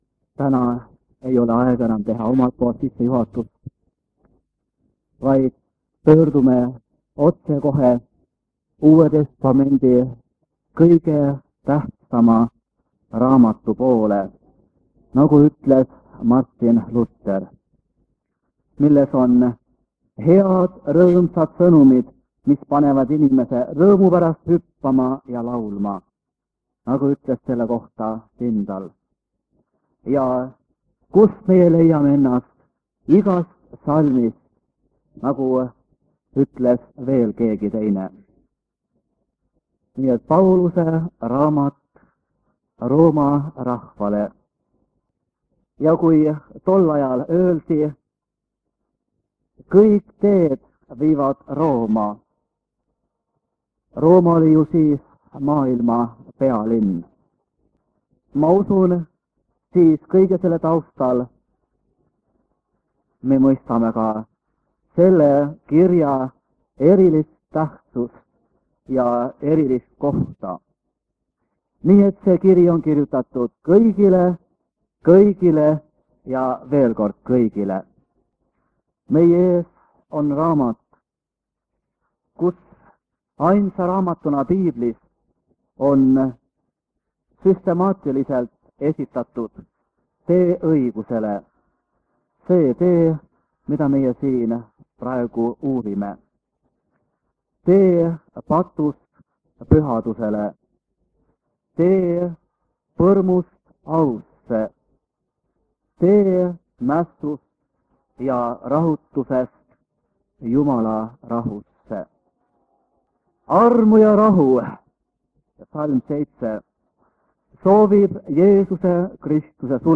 Jutlus vanalt lintmaki lindilt aastast 1975.
Jutlused